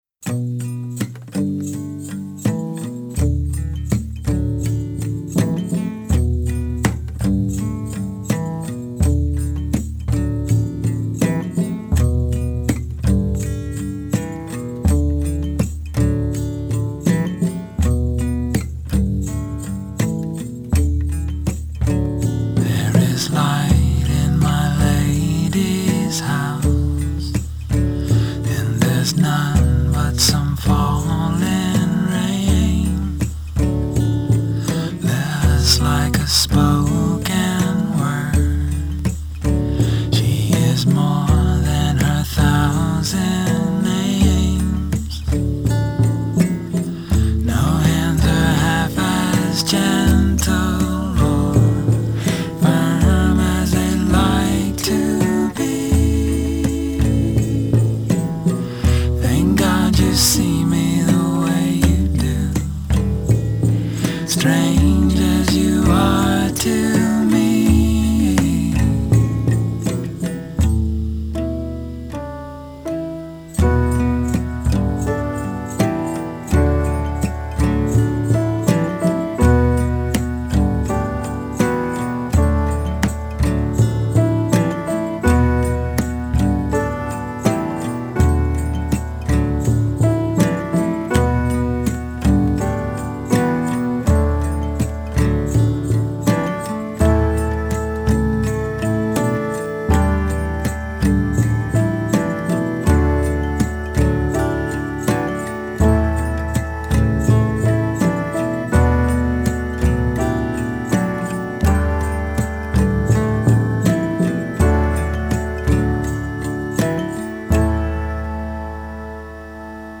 Sinuous, sexy voodoo music... God.